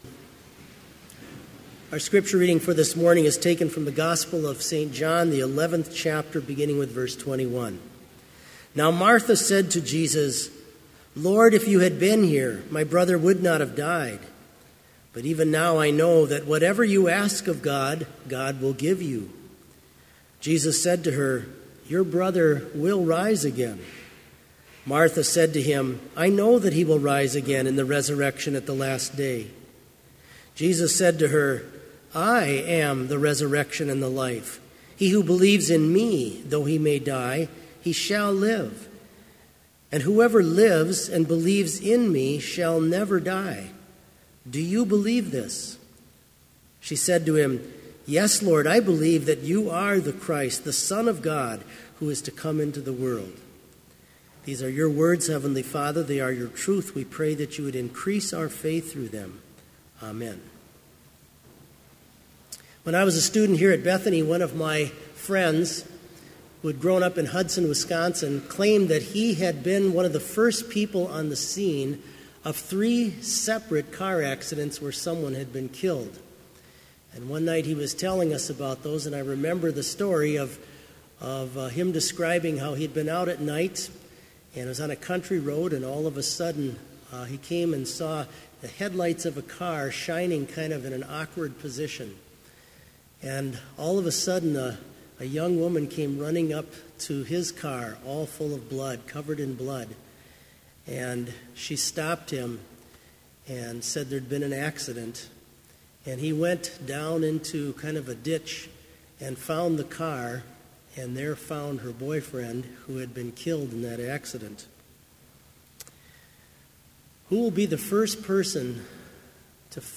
Complete Service
This Chapel Service was held in Trinity Chapel at Bethany Lutheran College on Monday, April 9, 2018, at 10 a.m. Page and hymn numbers are from the Evangelical Lutheran Hymnary.